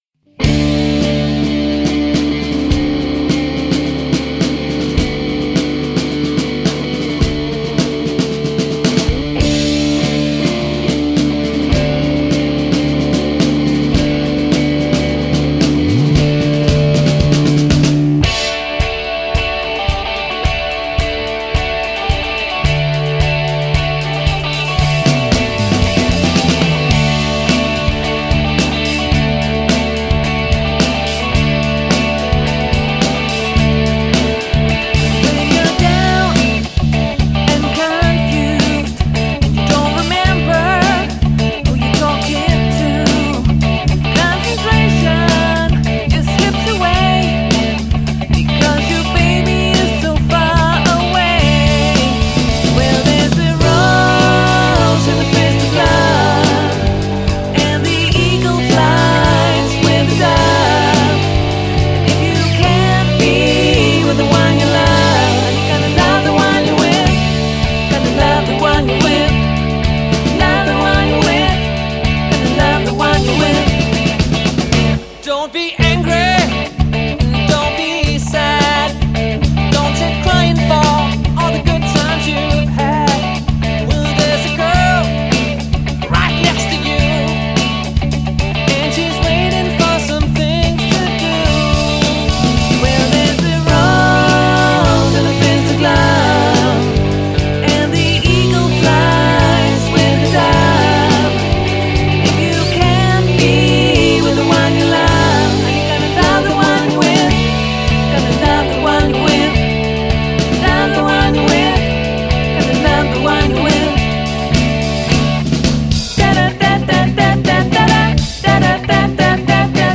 > 1 mandlig og 1 kvindelig forsanger
> 7-mands festband med masser af kor
• Allround Partyband